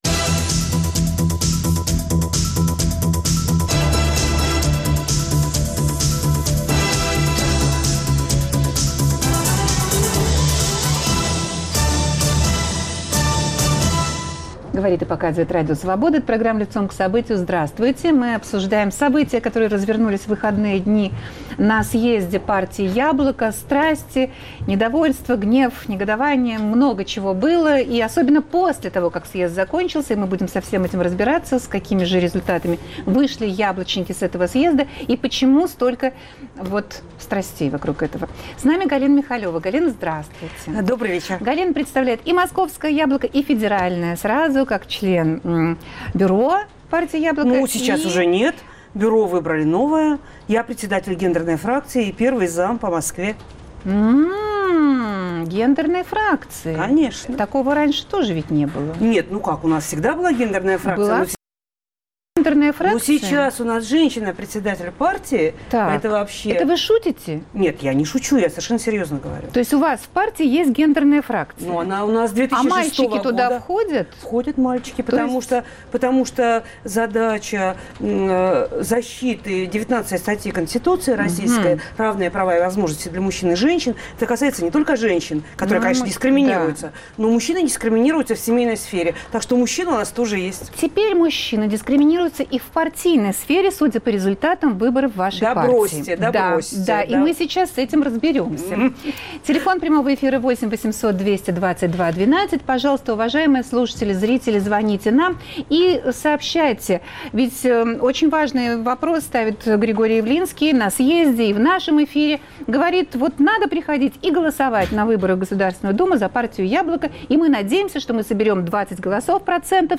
Способна ли партия Яблоко пройти в Государственную Думу? Дискуссия после съезда.